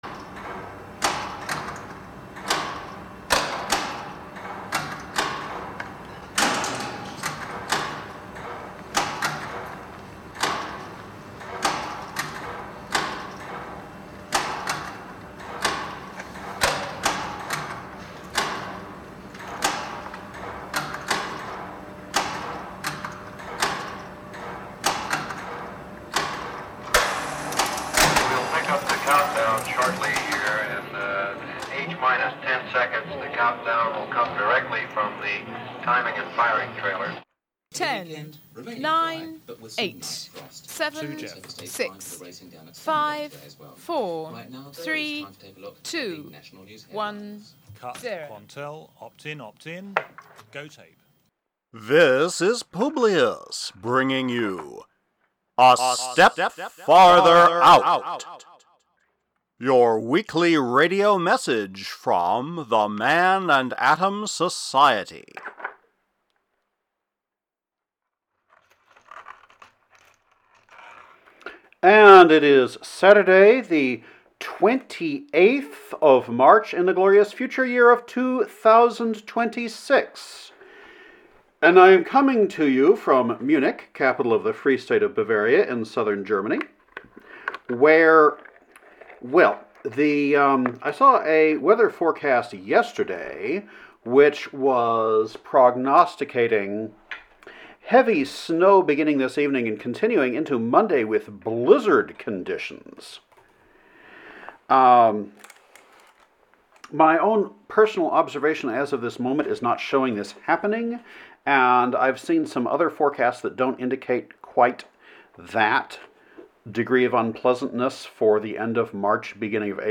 Archive Recording (incomplete due to network problems) 2026 ASFO Masterpost A Step Farther Out Masterpost Previous Week Following Week Patreon campaign